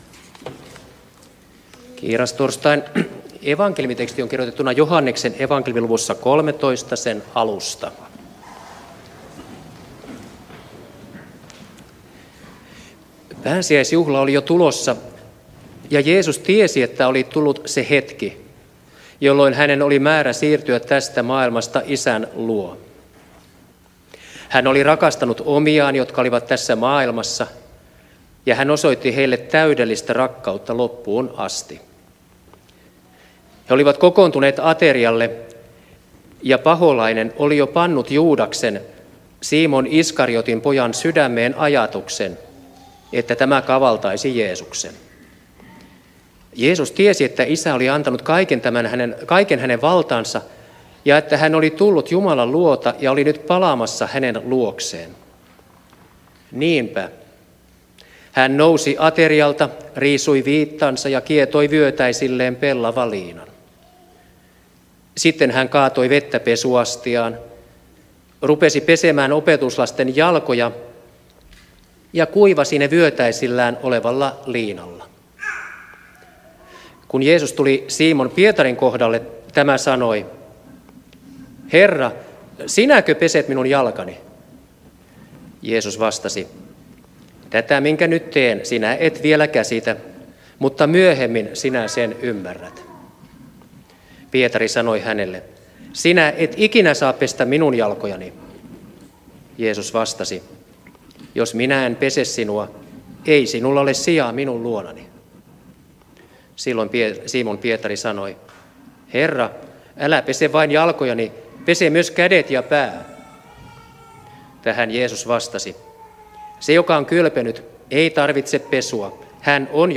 saarna Turussa kiirastorstaina Tekstinä Joh. 13:1–15